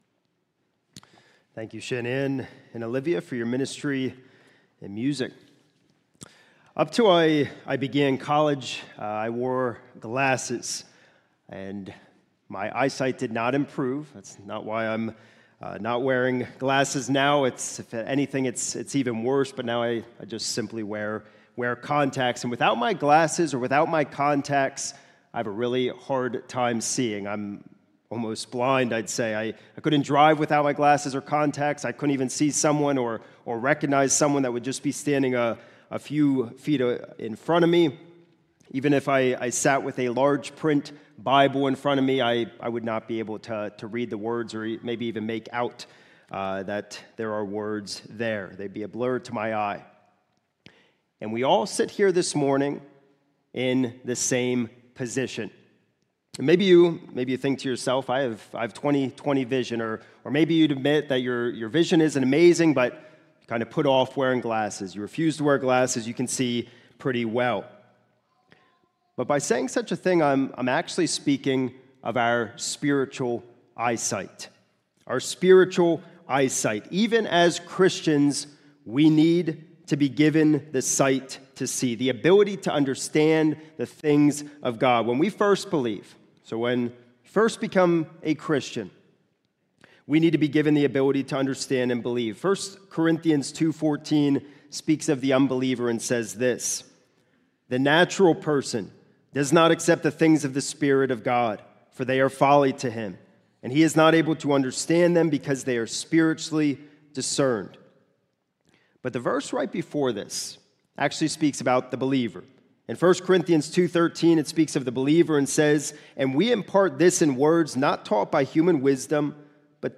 This is a sermon recorded at the Lebanon Bible Fellowship Church in Lebanon, PA during the morning service on 12/28/2025 titled